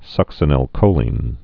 (sŭksə-nĭl-kōlēn)